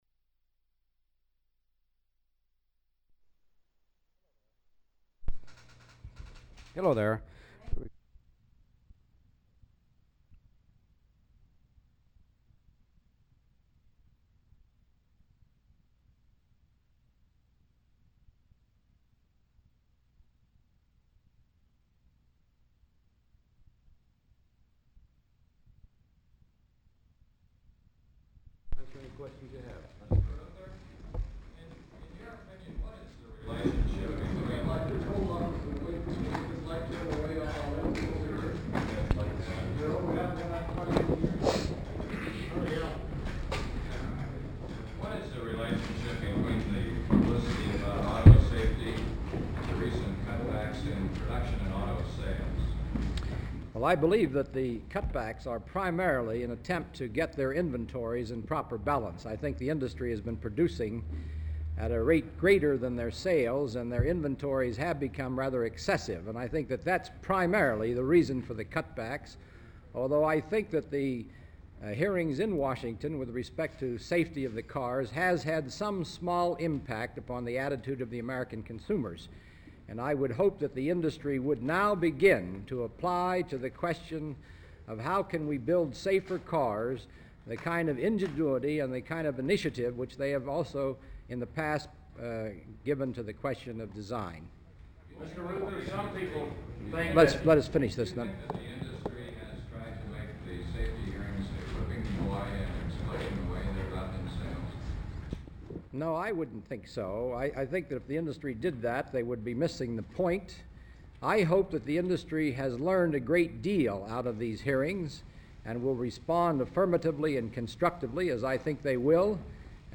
Walter P. Reuther Digital Archive · Walter P. Reuther - Press Conference, Reel 1 · Omeka S Multi-Repository